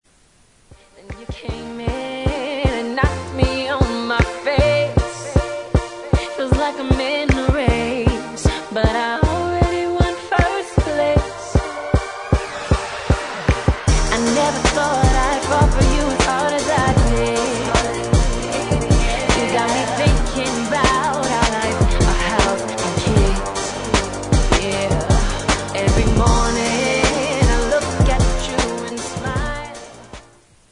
• R&B Ringtones